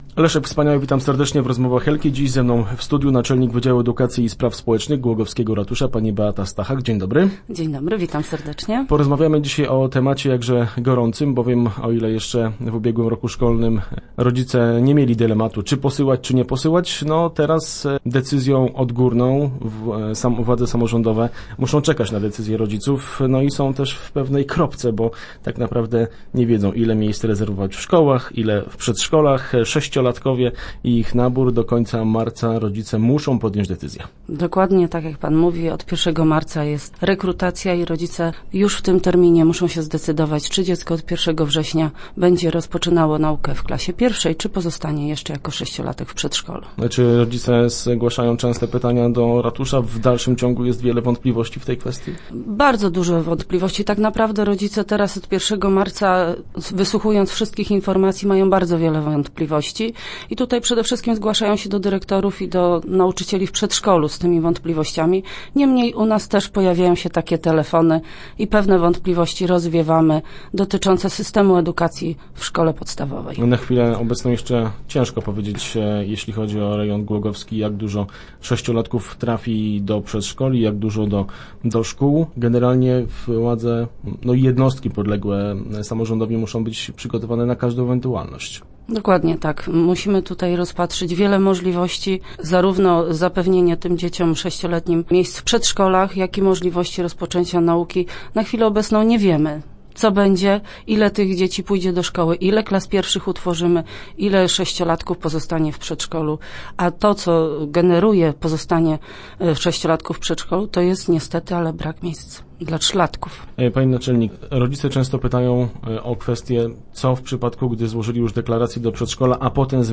w radiowym studiu